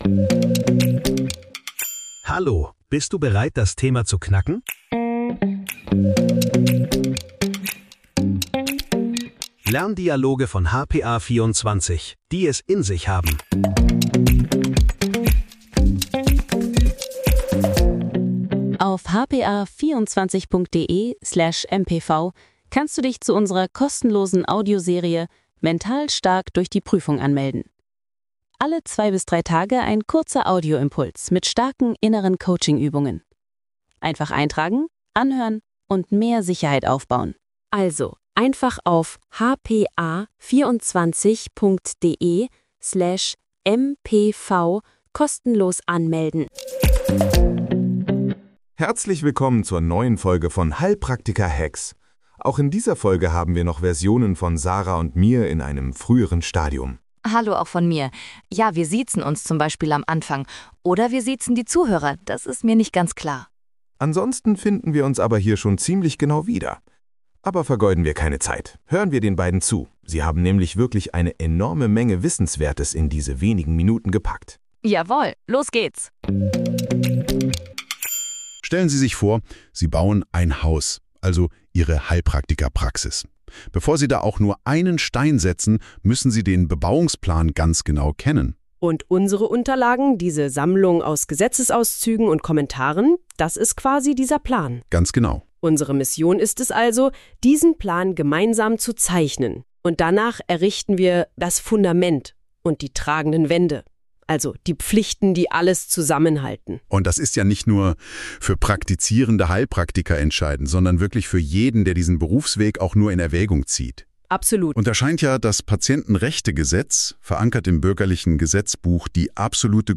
Lerndialoge für deinen Prüfungserfolg